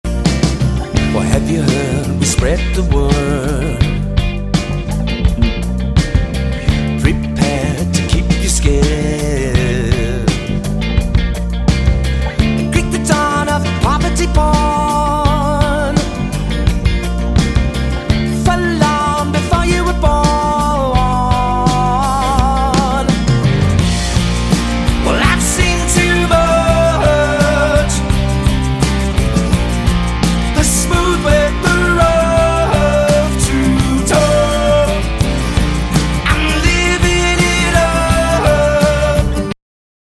abotsa, gitarrak eta teklatuak
baxua
bateria, perkusioak eta abotsak